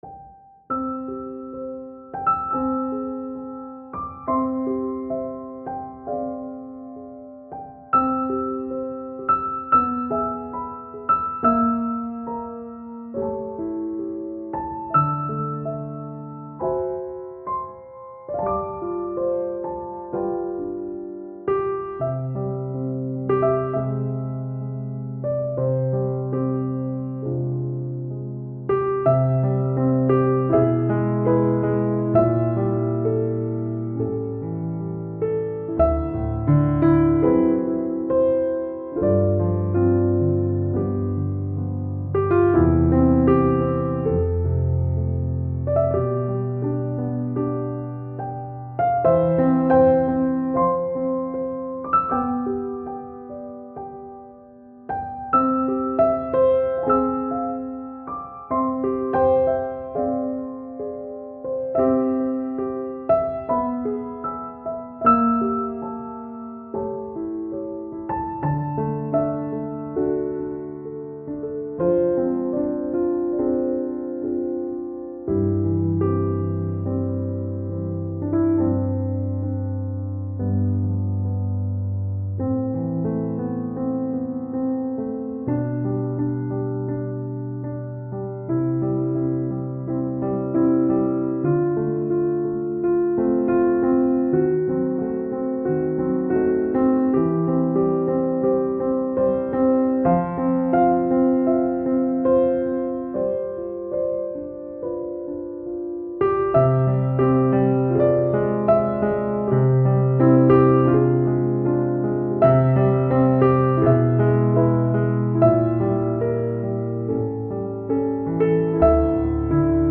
آرامش بخش , پیانو , عصر جدید , موسیقی بی کلام
پیانو آرامبخش